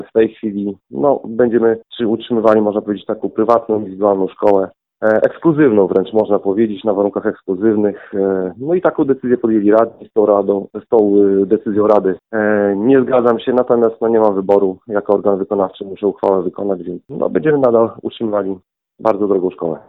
Jak mówi Karol Sobczak, burmistrz Olecka, będzie to bardzo droga, wręcz ekskluzywna szkoła.